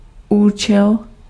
Ääntäminen
Synonyymit syfte Ääntäminen Tuntematon aksentti: IPA: /fɵŋkˈɧuːn/ Haettu sana löytyi näillä lähdekielillä: ruotsi Käännös Ääninäyte 1. funkce {f} 2. účel {m} Artikkeli: en .